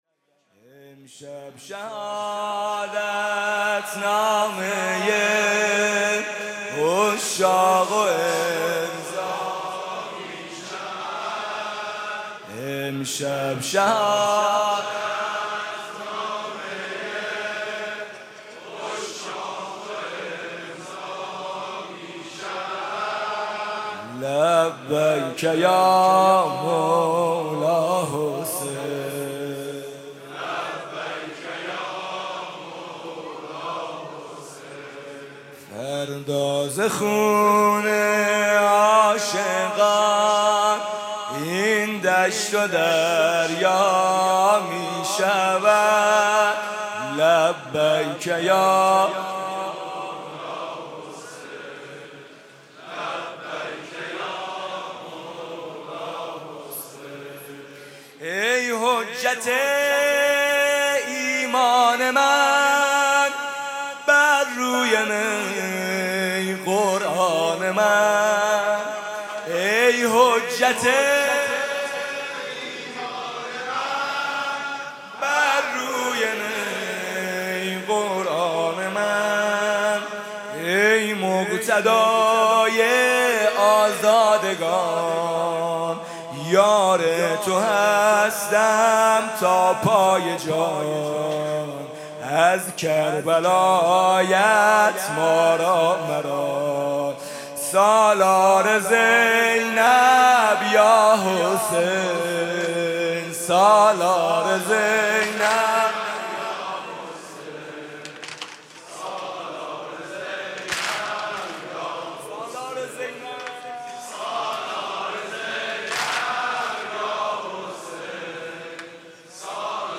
شب دهم محرم ۱۴۰۳
music-icon واحد: امشب شهادت‌نامه‌ی عشاق امضا می‌شود